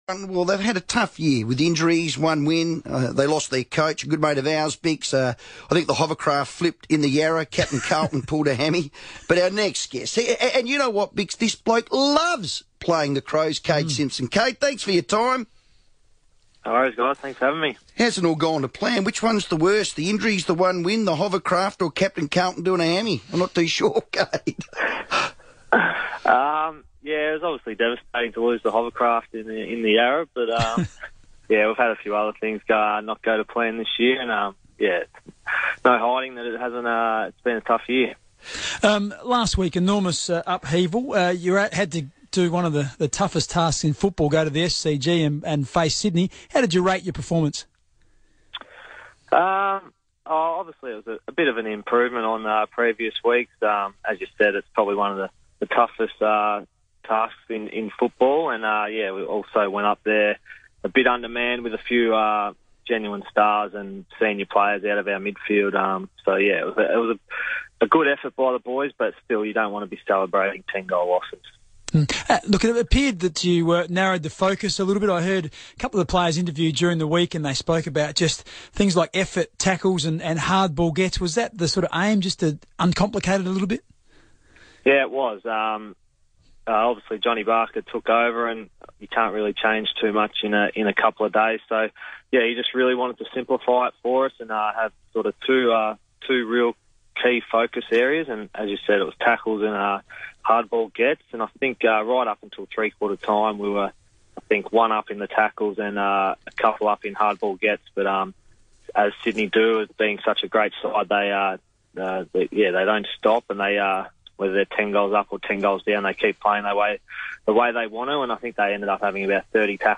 Carlton midfielder Kade Simpson speaks with FIVEaa ahead of the Blues' Round 10 clash with Adelaide.